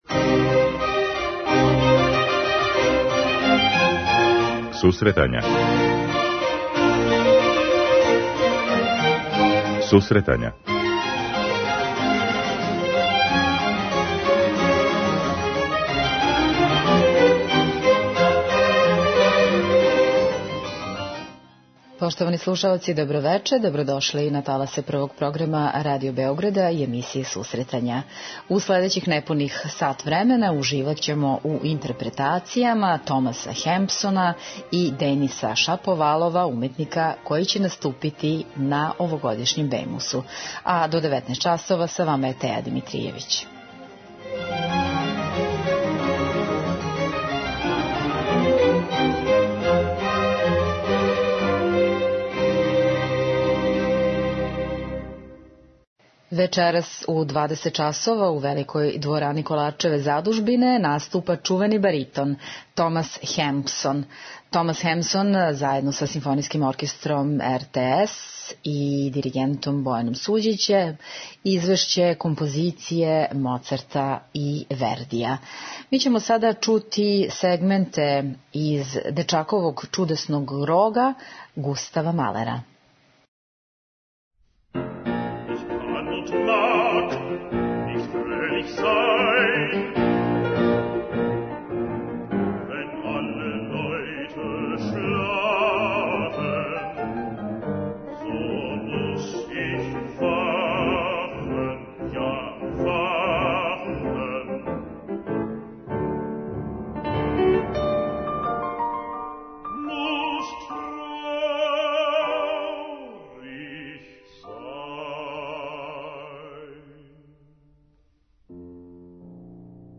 Музичка редакција Емисија за оне који воле уметничку музику.